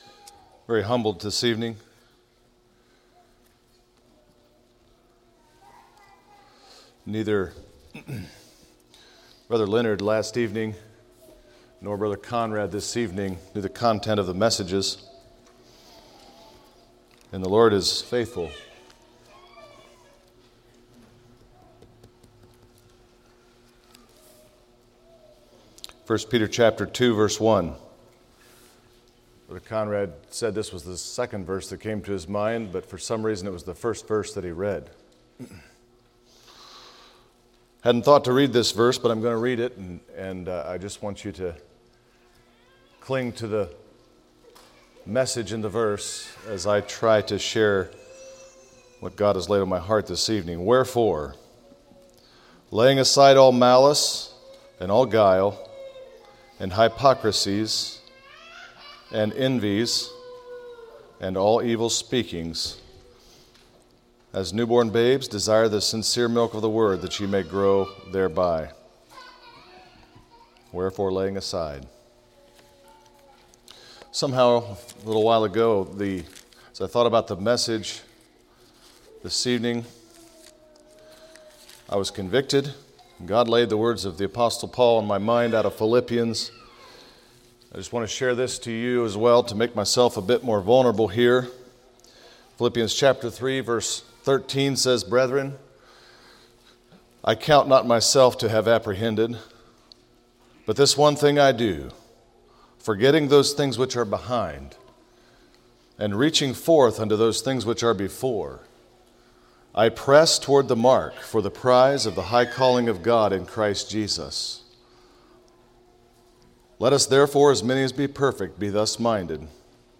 Sermons of 2021 - Blessed Hope Christian Fellowship